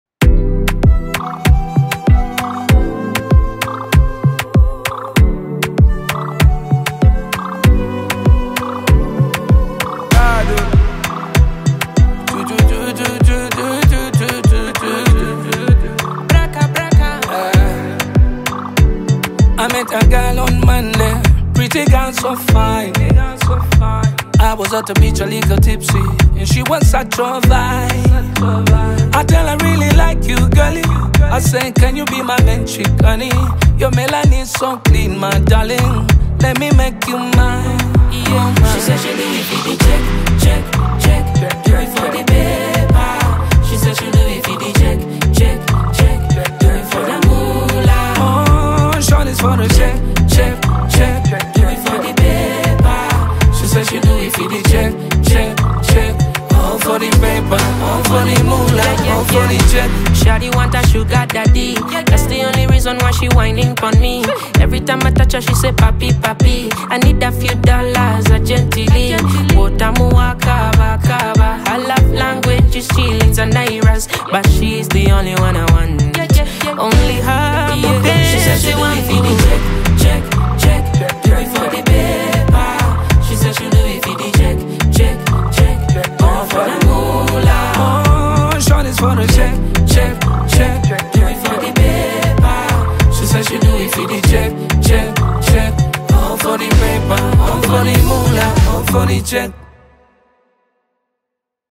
blends the swagger of Afropop with a modern R&B edge
trademark deep-toned delivery and stage-boss energy
smooth vocals and soulful style